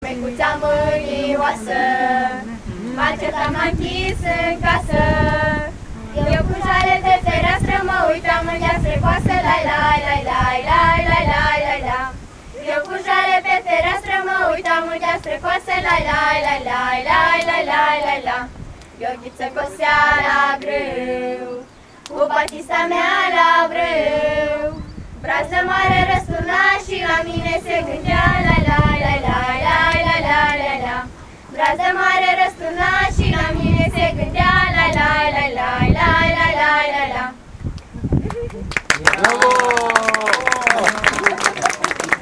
食後、村の子供達による「花いちもんめ」のような歌と踊りを楽しみ、若者たちとも交流しました。
◎　子供達の歌